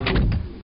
elevator_stp.wav